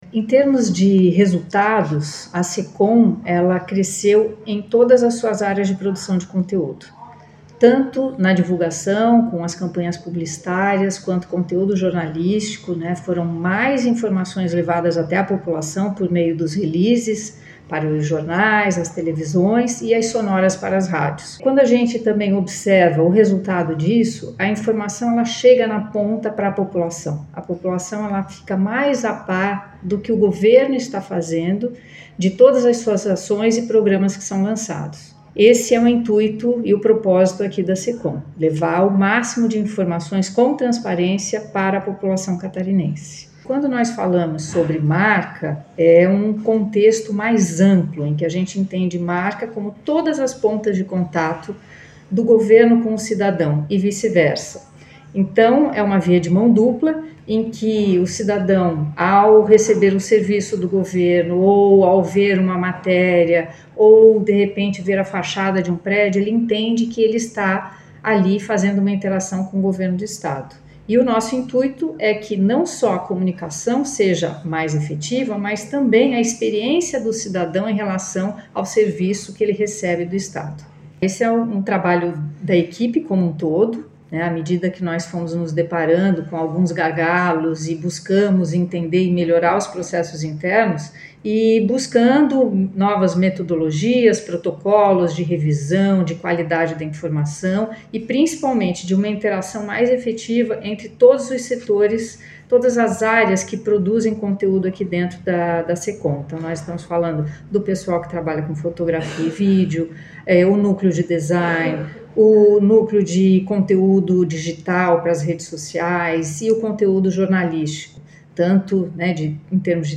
SECOM-Sonora-secretaria-adjunta-da-Comunicacao.mp3